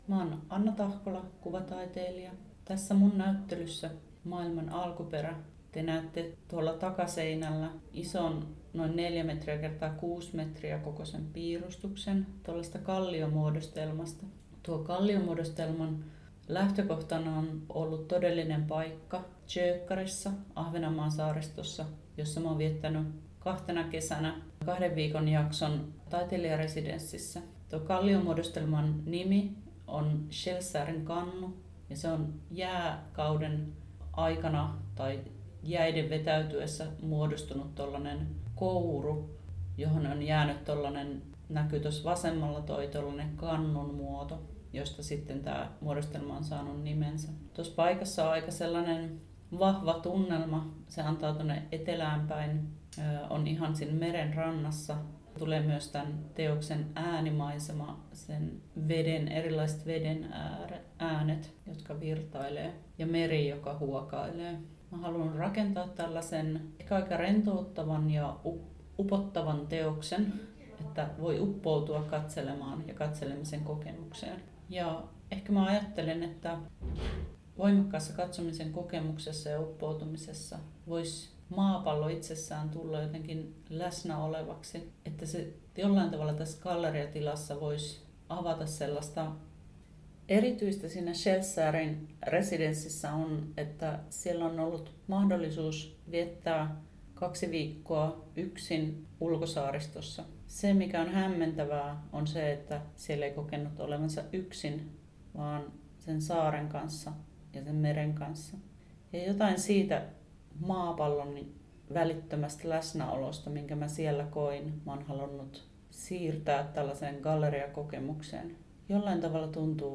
Ääniopaste